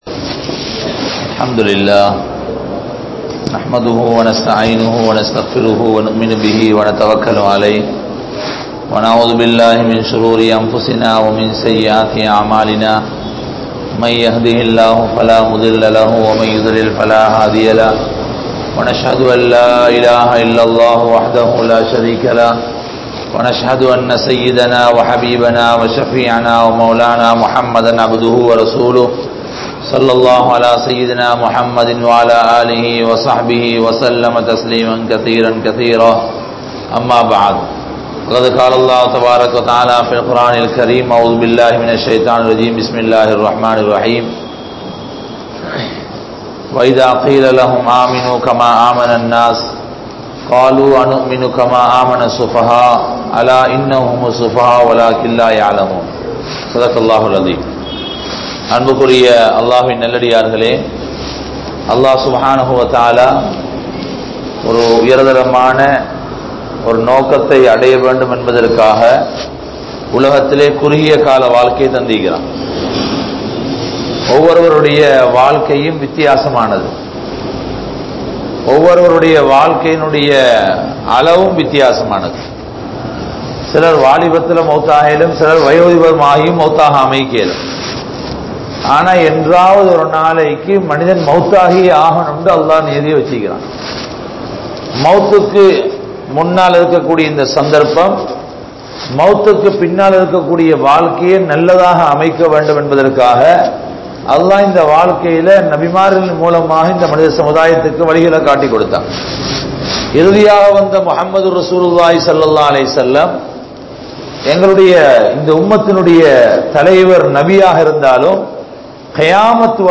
Sahabaakkal Seitha Thiyaahangal (ஸஹாபாக்கள் செய்த தியாகங்கள்) | Audio Bayans | All Ceylon Muslim Youth Community | Addalaichenai